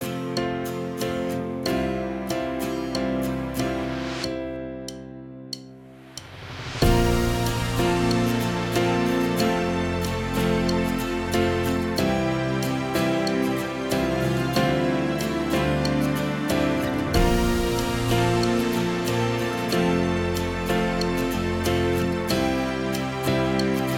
Minus All Guitars Pop (2010s) 3:37 Buy £1.50